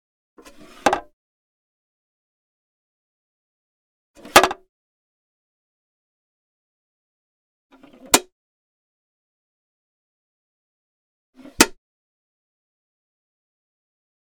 household
Computer Plastic Floppy Disk Holder Open Hinged Lid